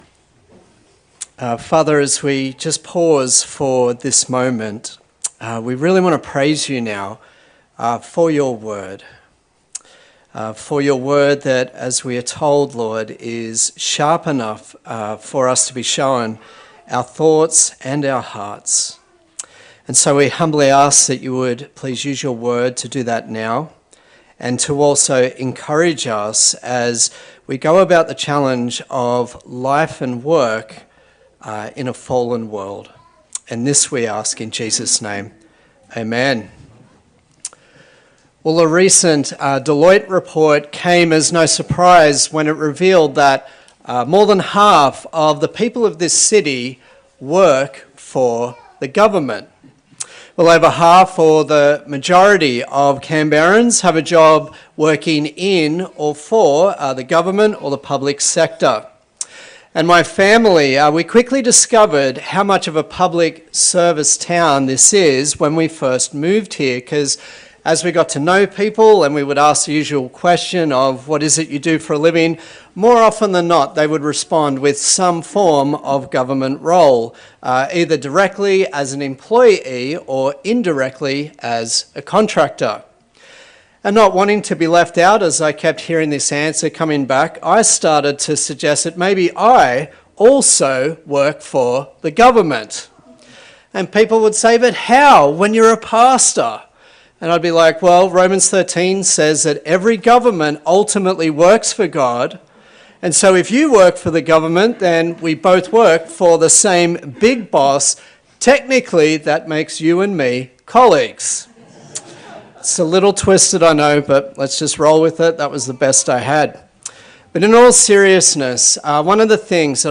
Daniel Passage: Daniel 6 Service Type: Sunday Service